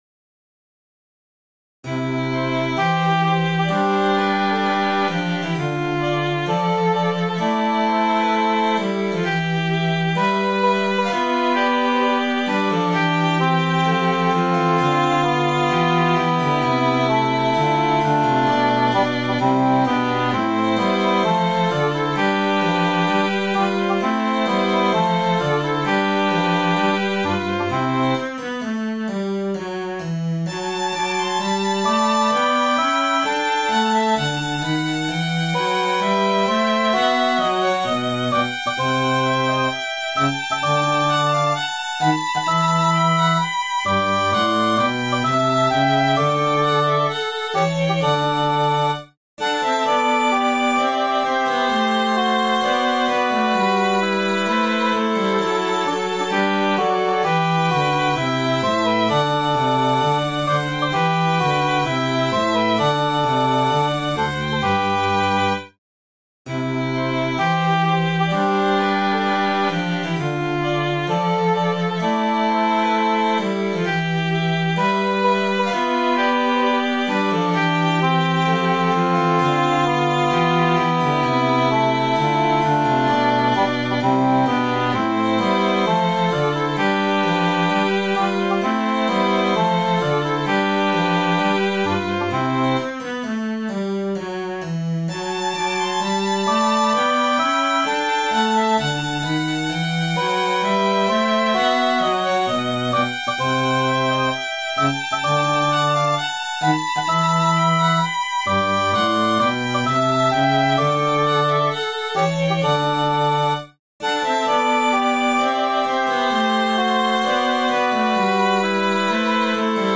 Sonata for 2Violin, English Horn and Continuo in C
Hi, here a baroque piece I wrote during my summer holiday.